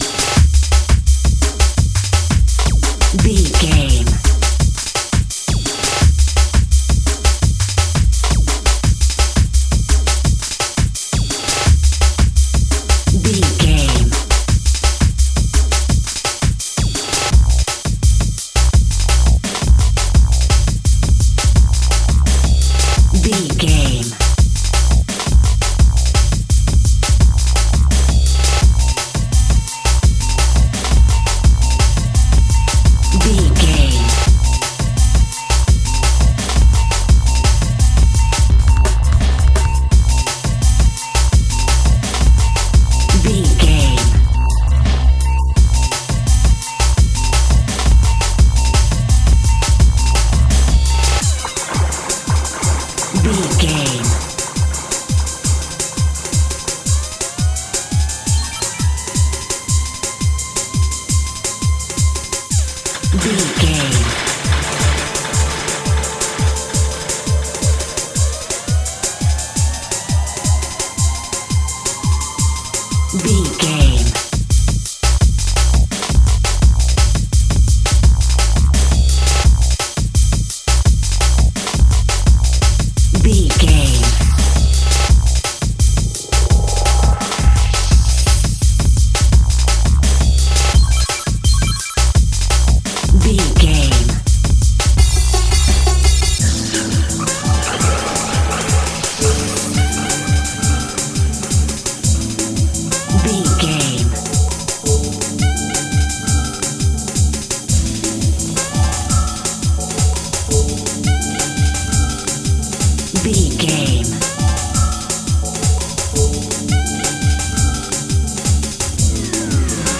Uplifting
Aeolian/Minor
drum machine
synthesiser
electric piano